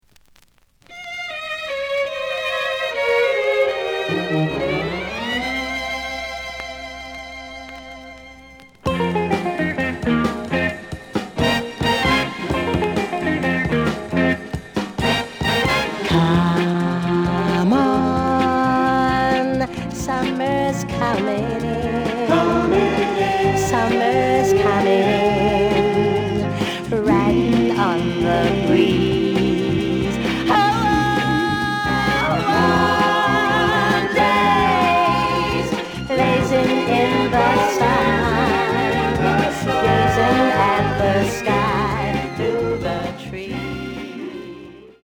The audio sample is recorded from the actual item.
●Genre: Soul, 60's Soul
Slight affect sound.